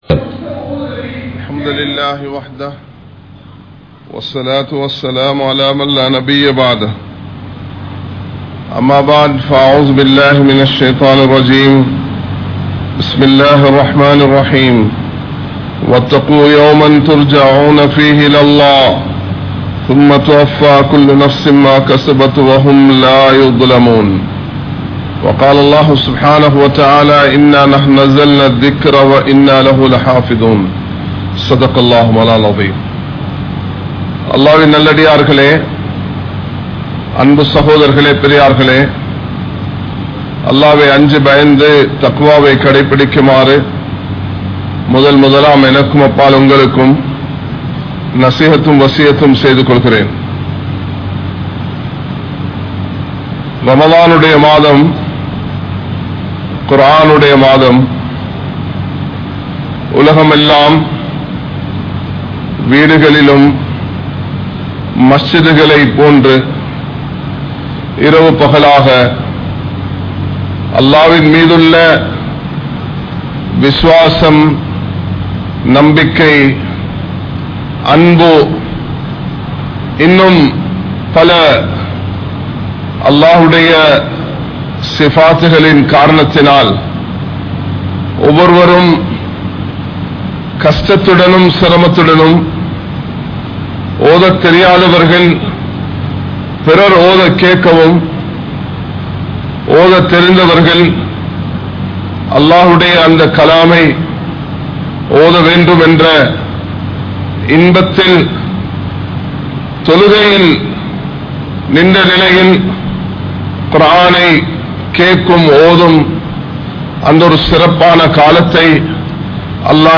Sahabaakkalai Maattriya Al Quran (ஸஹாபாக்களை மாற்றிய அல்குர்ஆன்) | Audio Bayans | All Ceylon Muslim Youth Community | Addalaichenai
Kollupitty Jumua Masjith